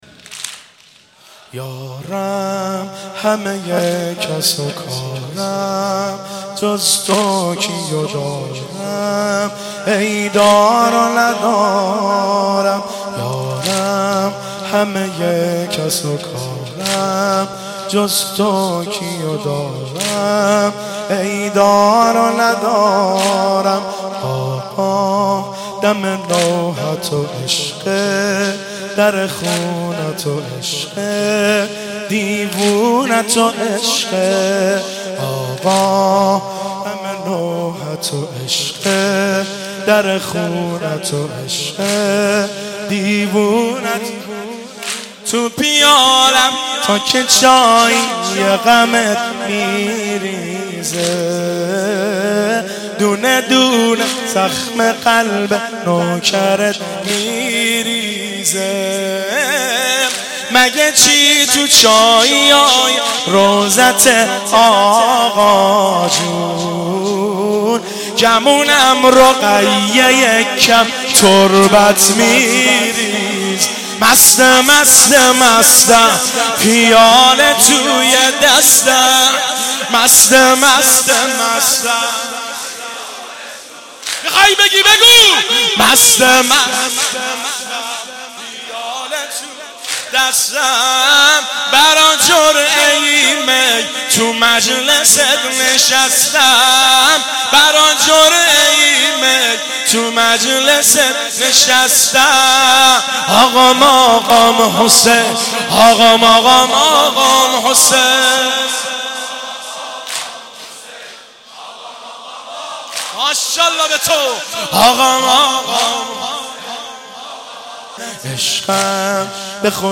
هیئت رهروان ولایت روستای قلعه پایان
گلچین مداحی محرم 1395